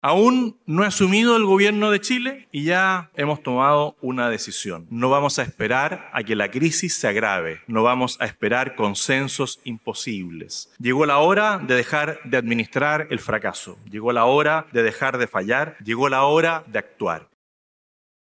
Lo anterior, en paralelo al debut internacional de José Antonio Kast, quien habló en el Foro Económico Internacional América Latina y el Caribe 2026.